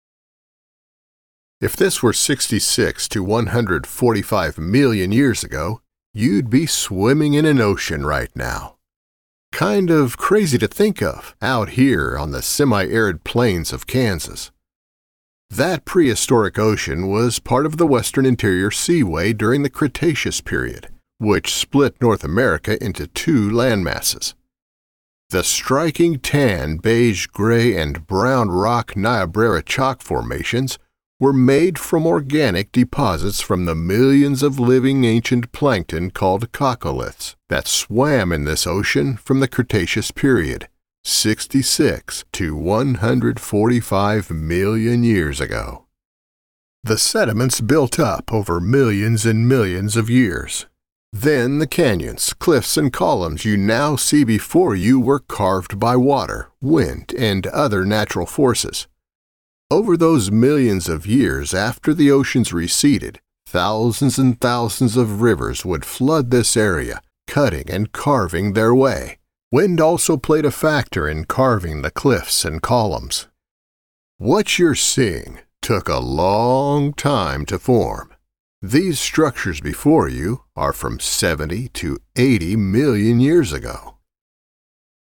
Unique rich character, warm genuine comfort
Unique. Rich. Warm. Comforting.
Smoky Valley Ranch Audio Tour | Story Site
• Professional home studio & editing, based in Lincoln Nebraska